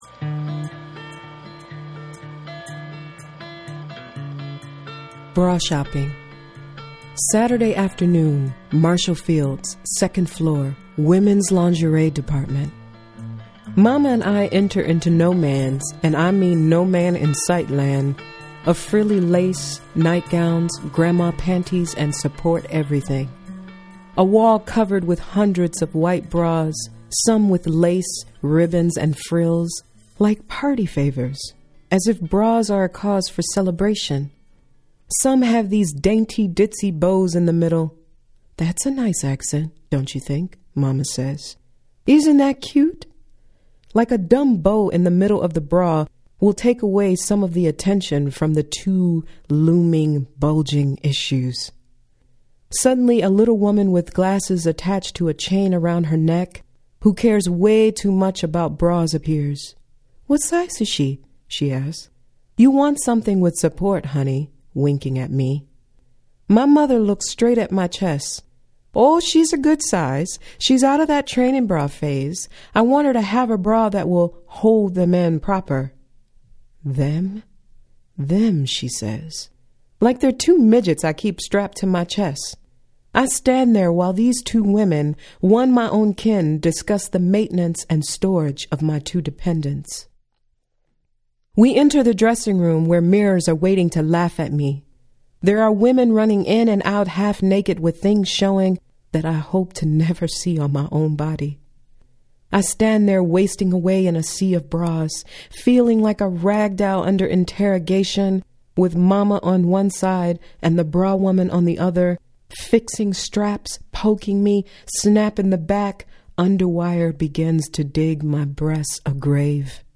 three-poems-about-Girrls.mp3